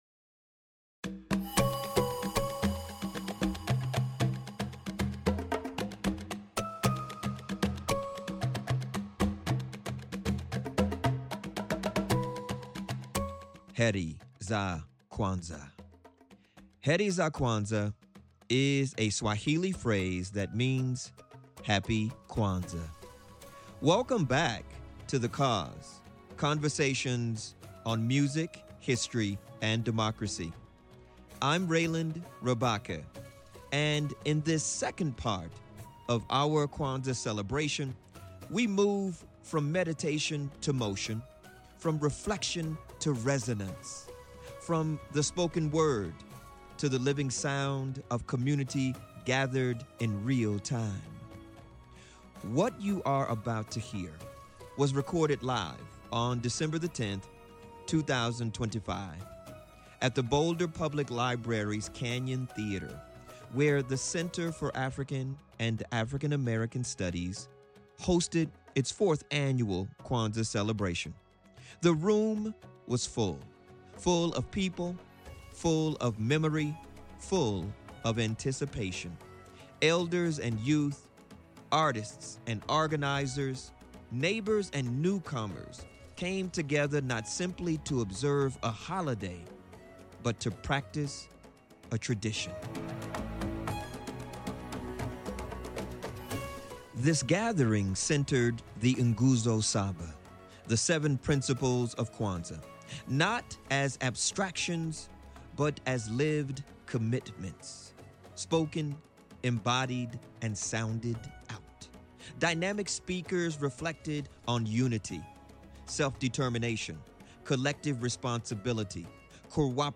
This special Part 2 episode of The Cause: Conversations on Music, History, and Democracy comes from a live community gathering held on December 10, 2025 at the Boulder Public Library. Rooted in dialogue, reflection, and shared practice, this episode centers community voices as they engage the principles of Kwanzaa together.
Audience members reflect on unity, purpose, creativity, and responsibility, offering insights grounded in lived experience, cultural memory, and community care. Rather than a lecture, this episode unfolds as a communal meditation on what it means to practice the Nguzo Saba in everyday life.